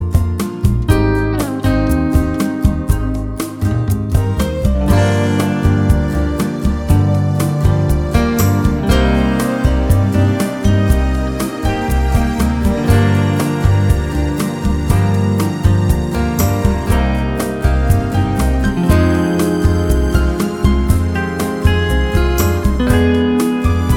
For Solo Singer Jazz / Swing 4:31 Buy £1.50